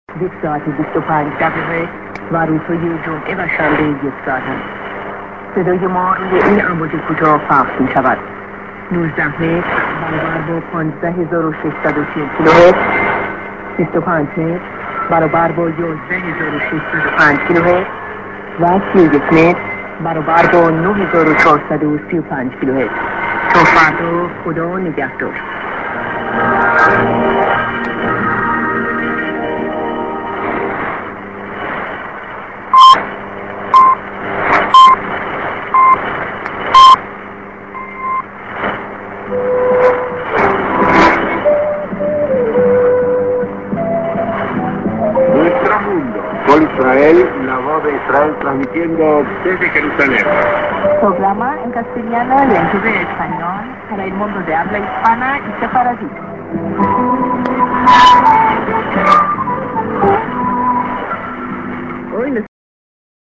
St.(５５分から:中継か) IS+SKJ->37":TS->ID(man)->